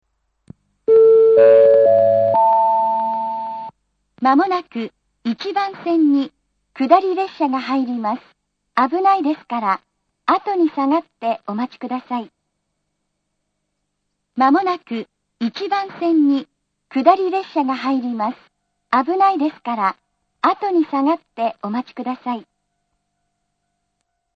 １番線接近放送
iwama-1bannsenn-sekkinn.mp3